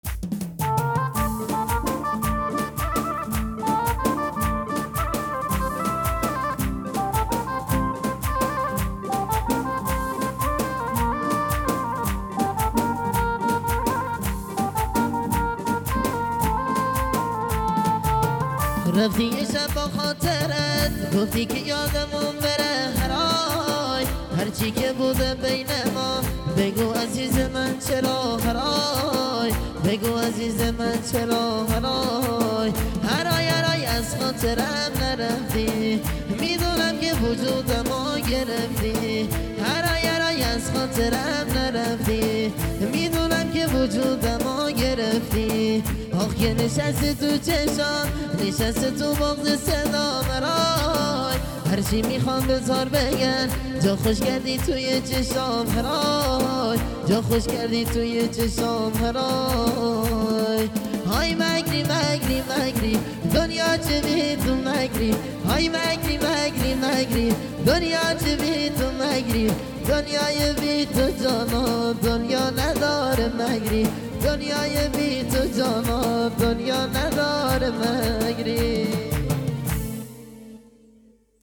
ترانه محلی زیبا و دلنشین